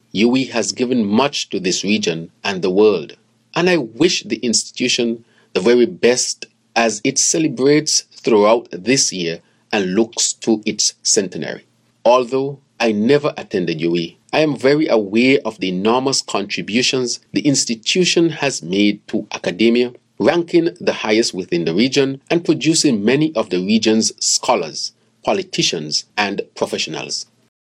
PM Drew delivers speech at UWI forum
Prime Minister Dr. Terrance Drew was the keynote speaker at the University of the West Indies’ Higher Education Forum on Thursday, July 20 held as part of UWI’s 75th anniversary celebrations.
UWI-Speech.mp3